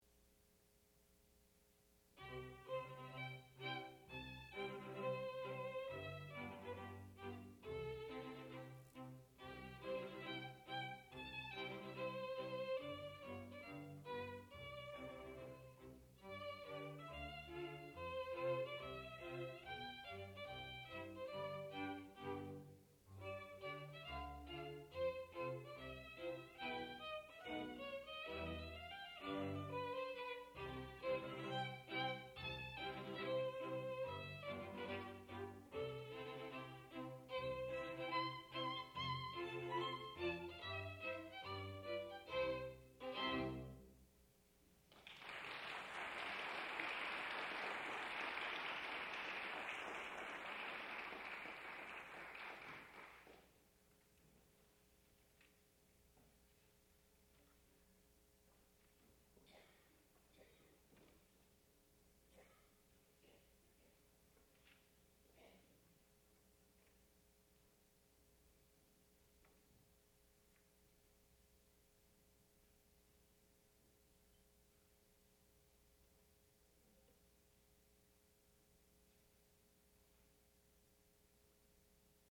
sound recording-musical
classical music
violin